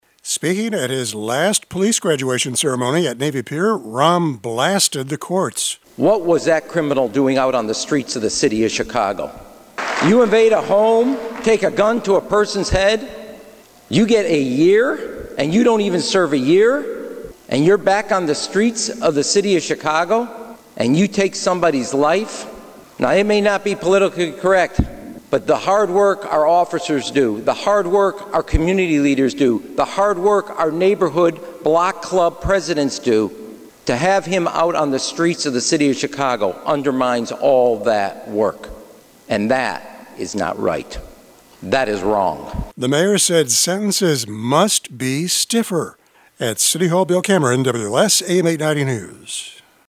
Speaking at his last police graduation ceremony at Navy Pier, Rahm blasted the courts.